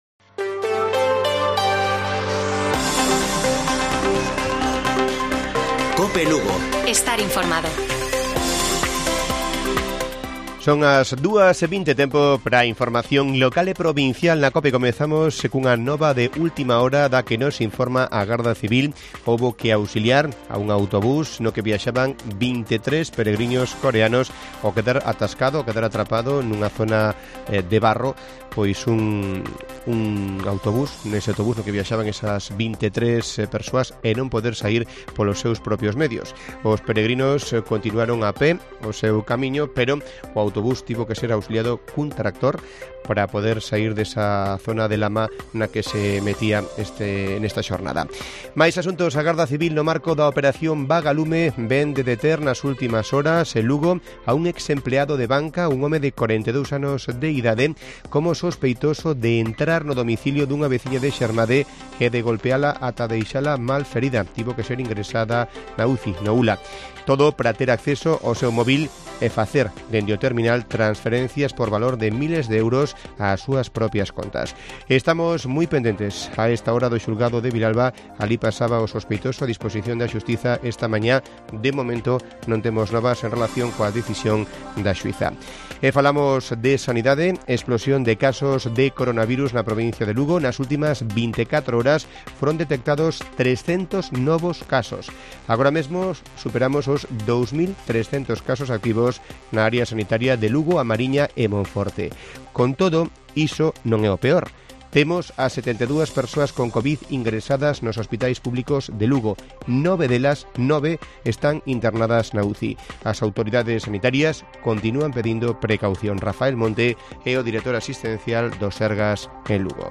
Informativo Mediodía de Cope Lugo. 27 DE ABRIL. 14:20 horas